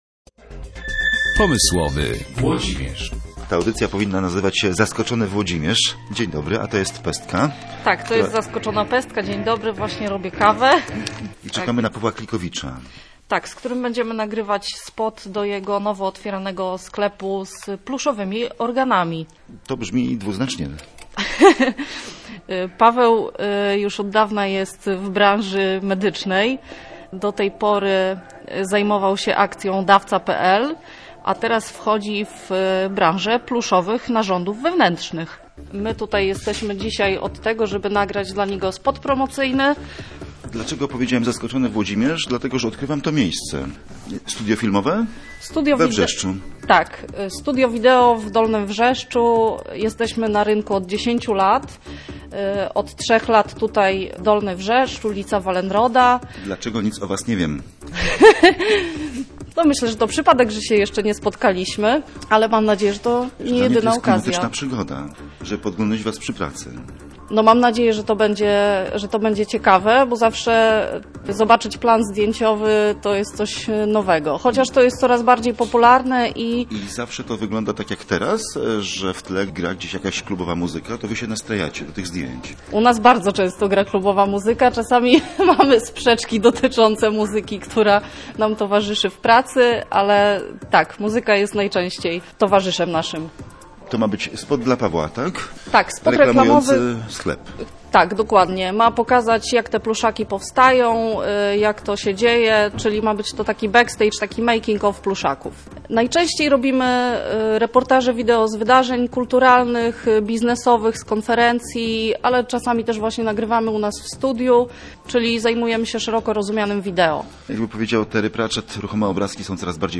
Posłuchaj rozmowy przeprowadzonej podczas powstawania filmu o pluszowych organach./audio/dok2/pomyslowyorgany.mp3